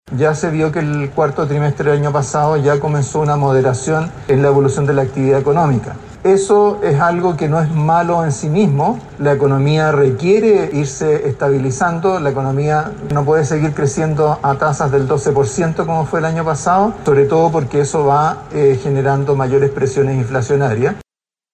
El Ministro de Hacienda, Mario Marcel, dijo que es positivo que el crecimiento se modere, ya que así se evita un mayor porcentaje de inflación.